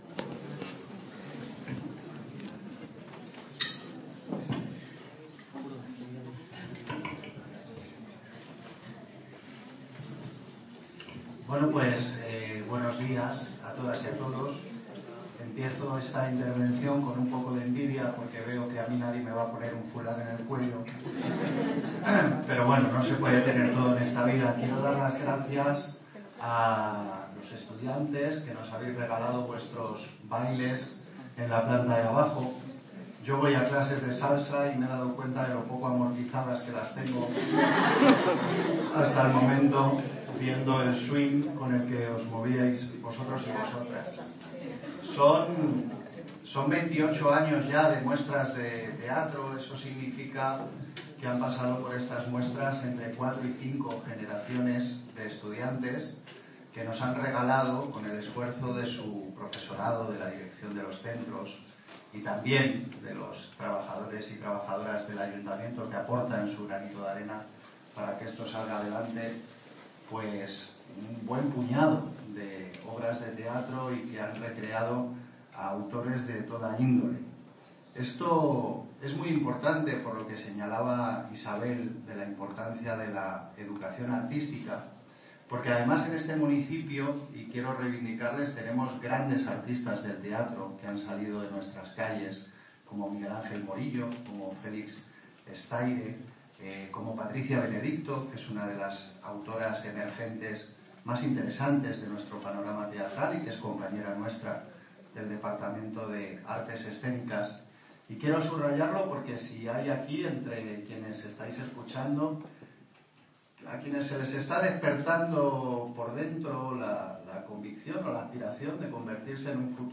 Audio - Gabriel Ortega (Concejal de Cultura, Bienestar Social y Vivienda) Sobre XXVIII Muestra de Teatro Institutos